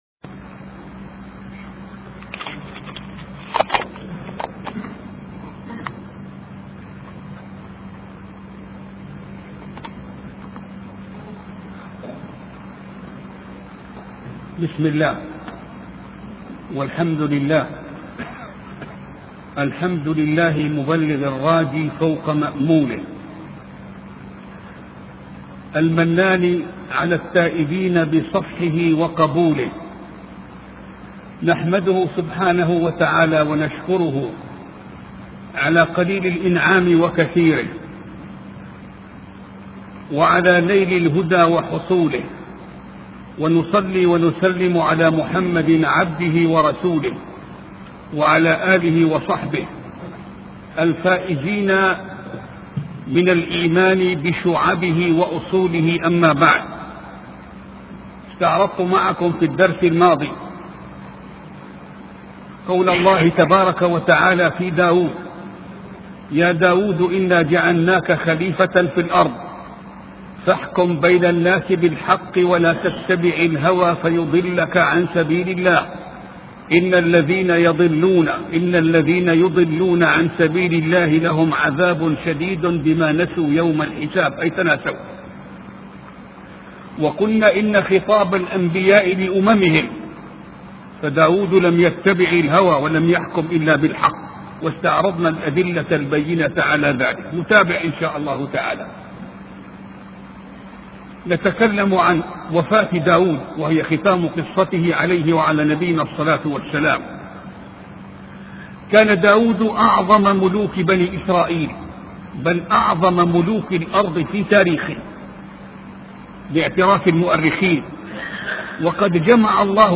سلسلة محاضرات في قصة داود عليه السلام